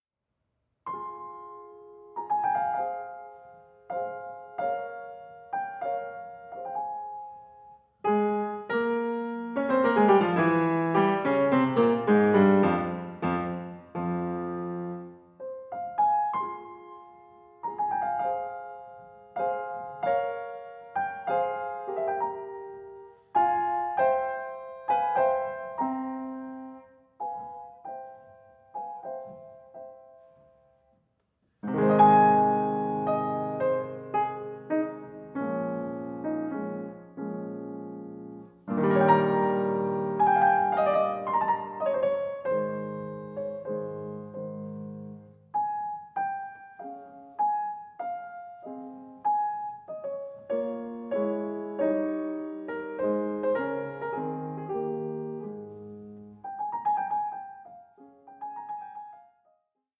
Piano Sonata in F Major